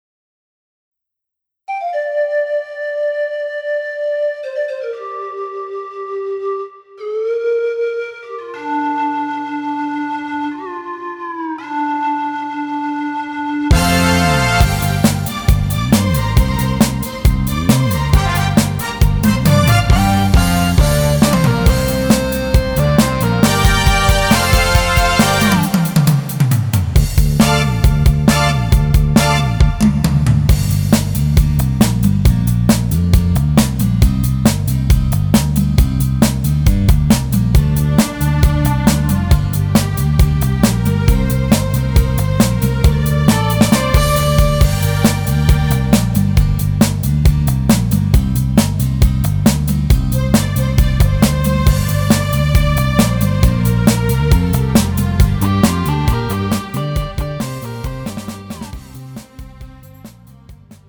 음정 -2키
장르 가요 구분 Pro MR